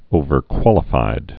(ōvər-kwŏlə-fīd)